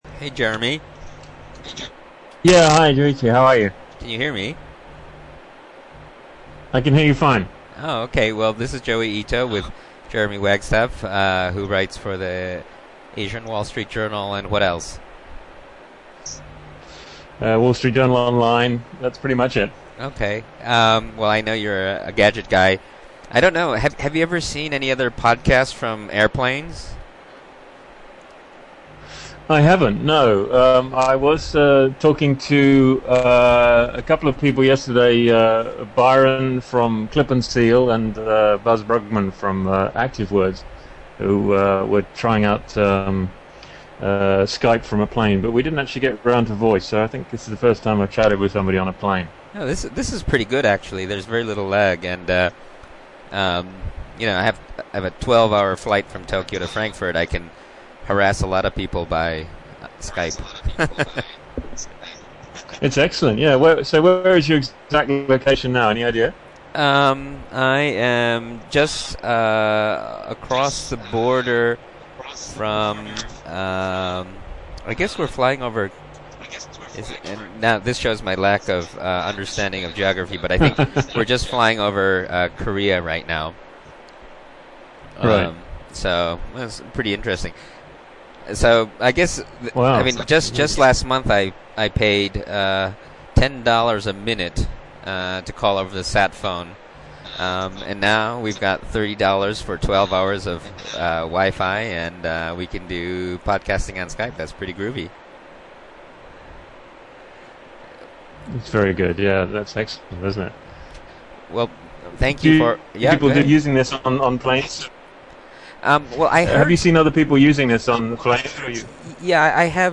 I just completed my first successful Skypecast from an airplane. I used Audio Hijack Pro, Skype and the Boeing Connexion service on Lufthansa flight 711.
There is a bit of a delay, but this time it was barely noticable.
The sound quality is excellent.